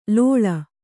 ♪ lōḷa